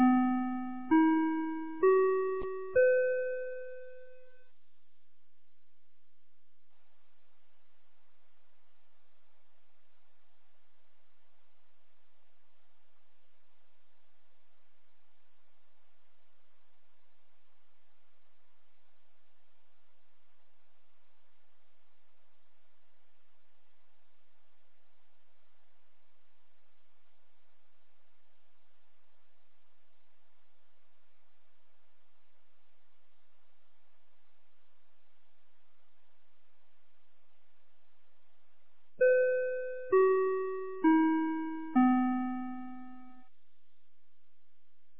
2023年01月10日 10時01分に、九度山町より全地区へ放送がありました。
放送音声